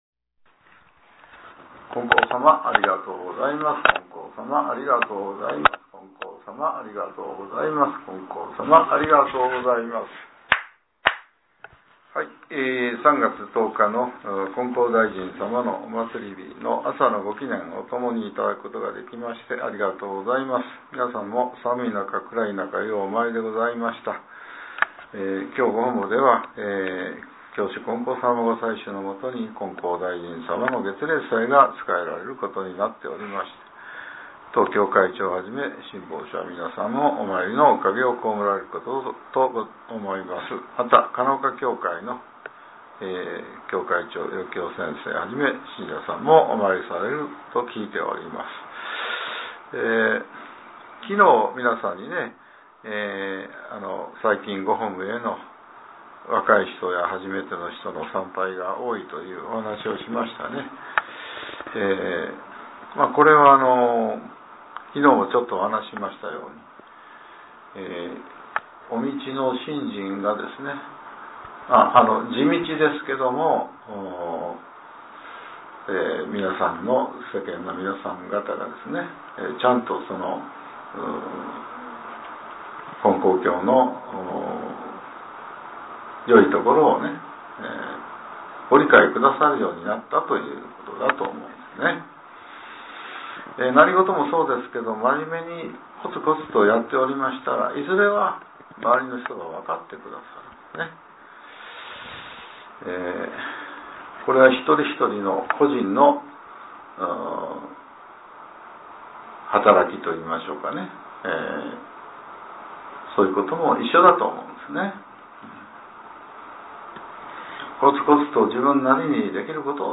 令和７年３月９日（朝）のお話が、音声ブログとして更新されています。